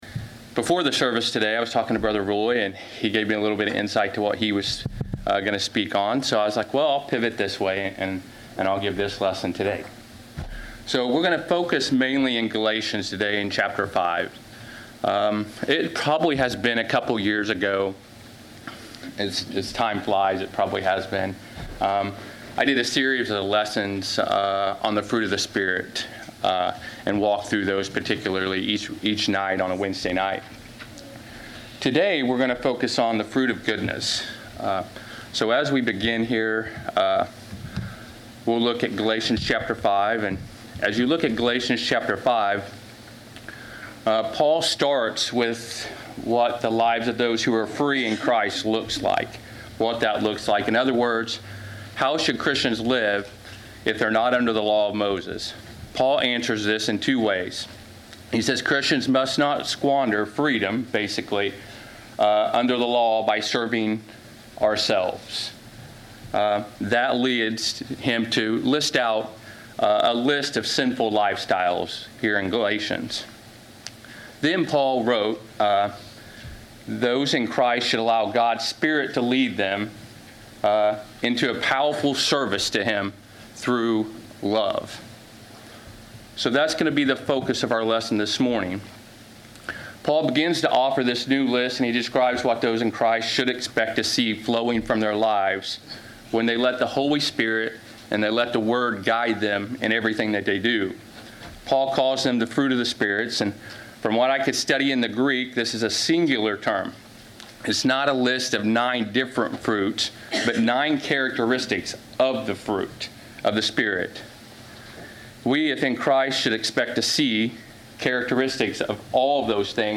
Passage: Galatians 5:22 Service Type: Sunday 11:00 AM